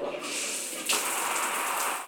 showerstart.wav